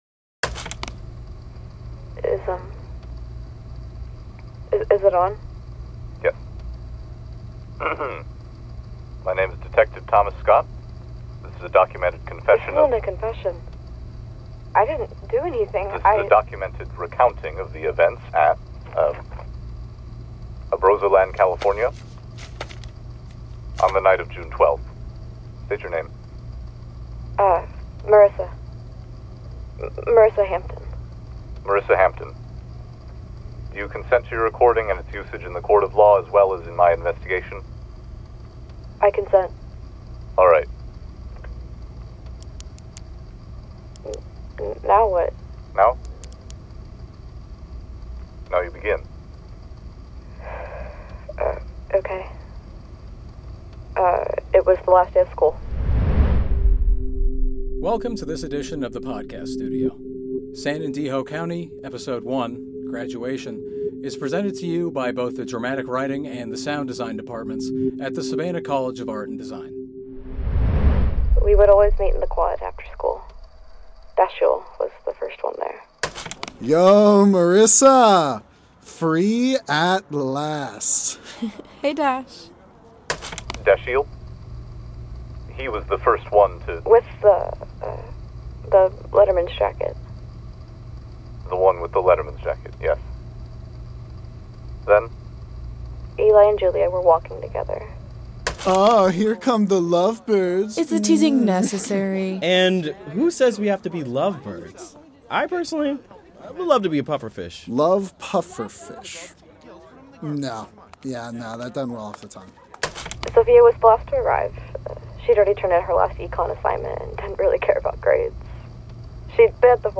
Library of Audio Fiction Podcasts